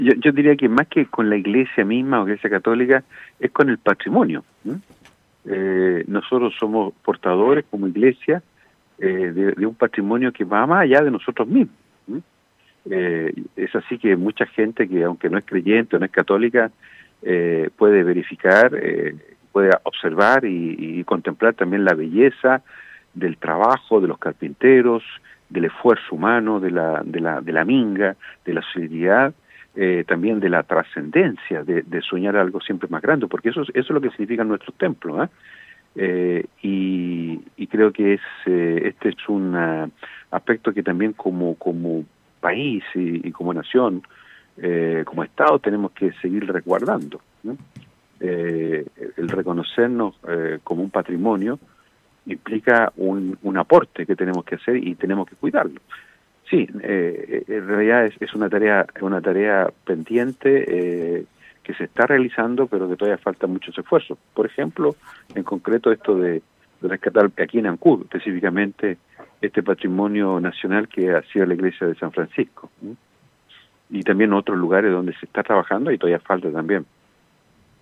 En el marco de esta conmemoración, conversamos en Radio Estrella del Mar, con Monseñor Juan María Agurto, quien comenzó haciendo un repaso por los capítulos iniciales en la historia de esta Diócesis.